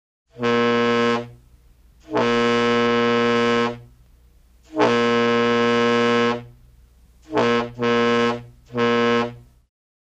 Звуки грузовика, фуры